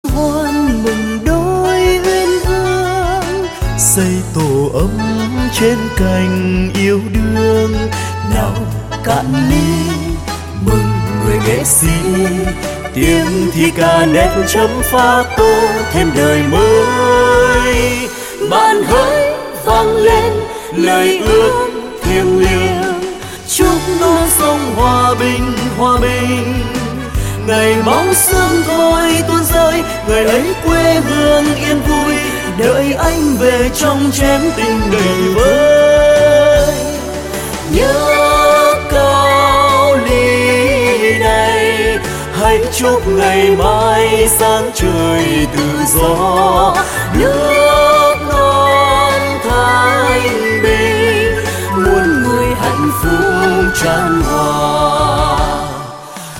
Thể loại nhạc chuông: Nhạc trữ tình